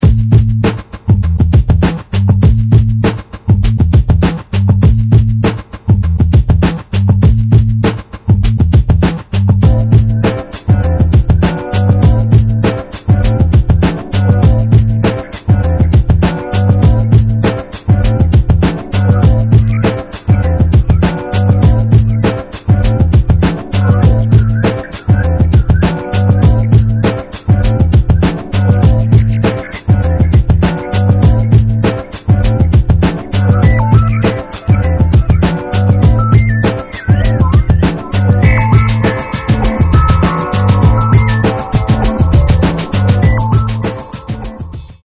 2005 harmonic medium instr.